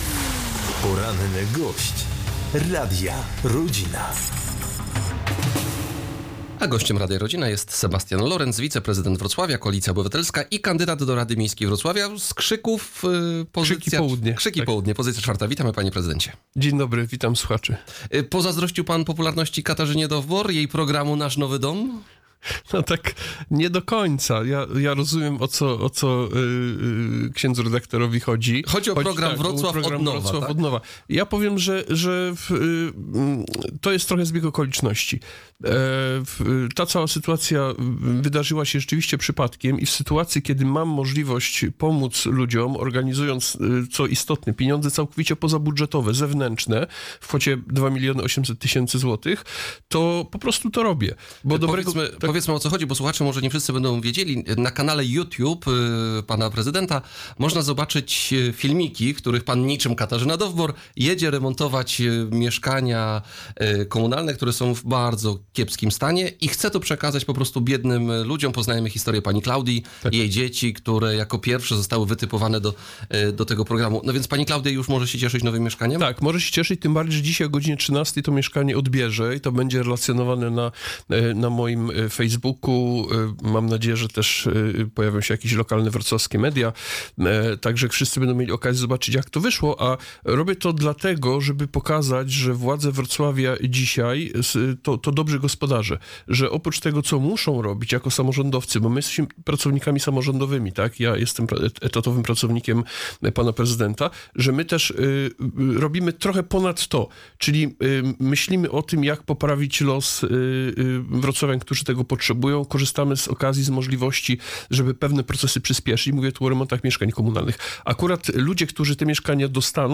O programie remontów wrocławskich kamienic oraz swojej prywatnej inicjatywie pomocy w renowacji mieszkań dla potrzebujących mieszkańców Wrocławia mówił dziś w porannej rozmowie wiceprezydent Wrocławia Sebastian Lorenc. Na zakończenie wystawił też ocenę prezydentowi Jackowi Sutrykowi za dokonania w ostatniej kadencji oraz odniósł się do publikacji prasowych dotyczących planów mianowania po wyborach marszałka województwa dolnośląskiego.